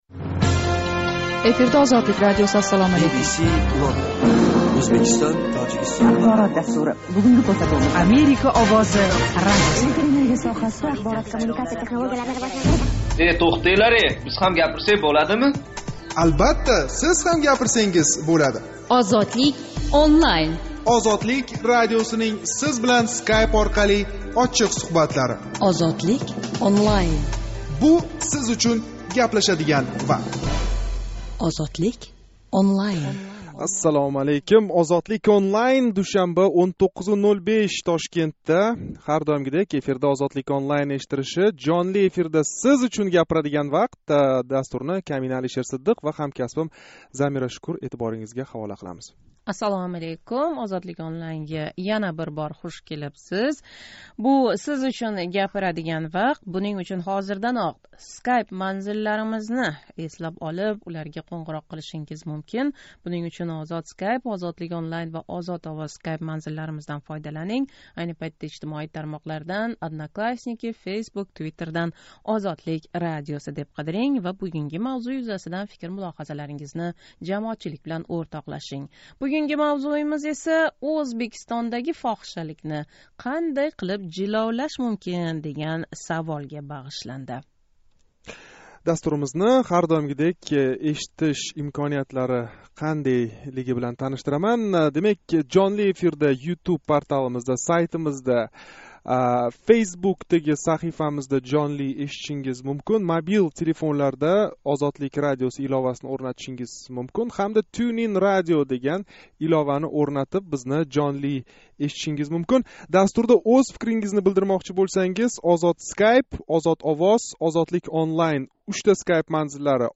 Бу чоралар қанчалик самара бераëтир? Душанба кунги жонли суҳбатда шу ҳақда гаплашамиз.